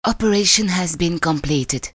the velvetly, sultry voice with the French accent inform you that "the operation has been completed" and you, as any red-blooded male would do, click OK, everything is gone and you get your initial disc space back.
onoperationcomplete_0409.mp3